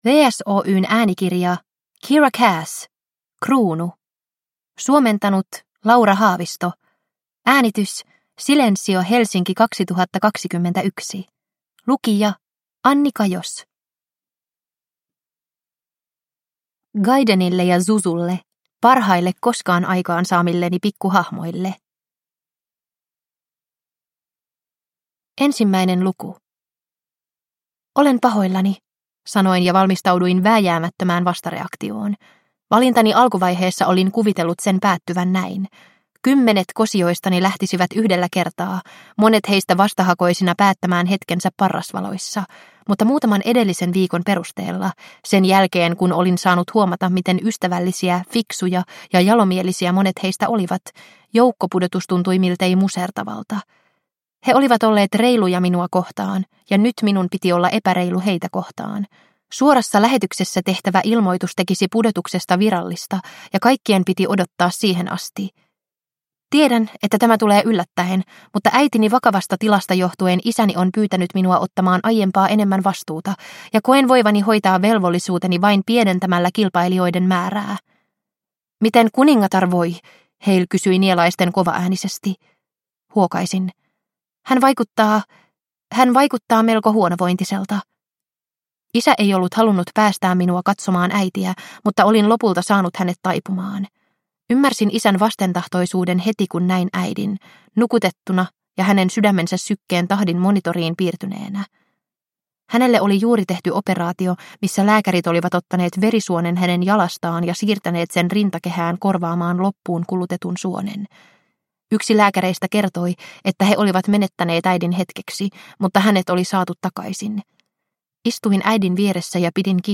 Kruunu – Ljudbok – Laddas ner